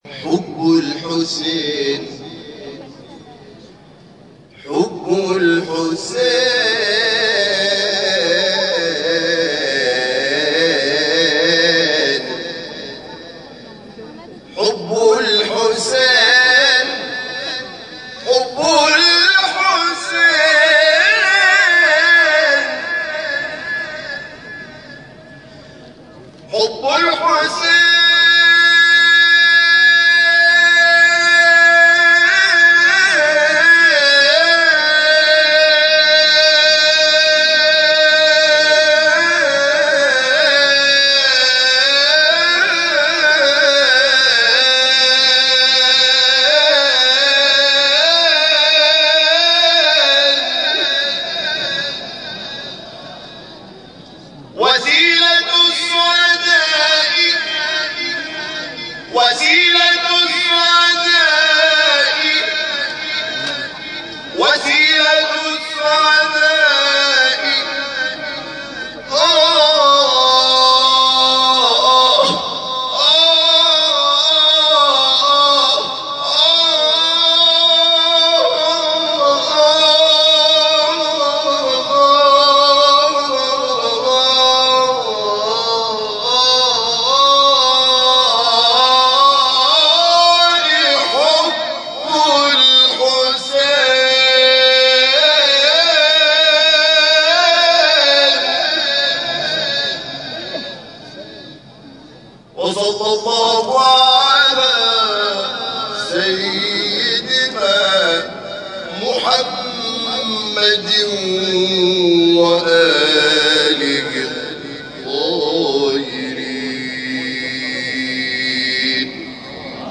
گروه جلسات و محافل: مراسم تشییع پیکر شهید محسن حججی، شهید مدافع حرم با تلاوت قرآن حامد شاکرنژاد، برگزار شد.
ابتهال حب الحسین